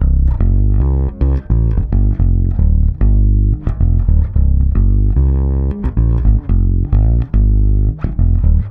-AL AFRO D.wav